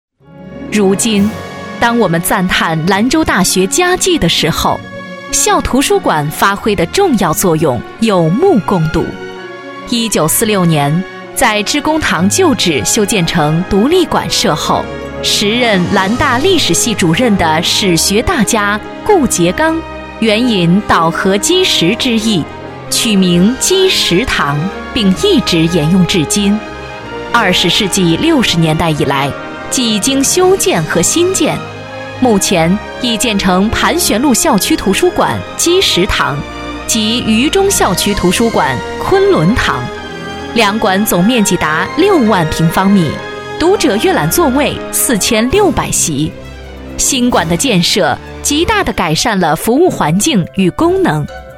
学校宣传片女20号（兰州大
成熟稳重 学校宣传
干练严肃女音，大气稳重。